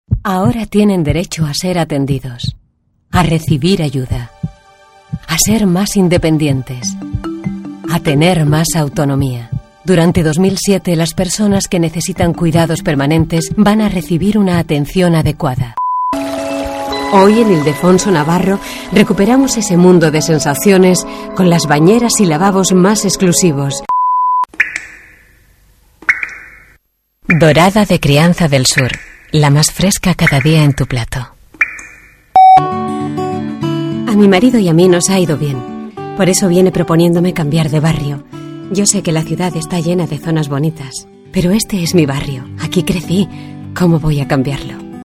Voces Femeninas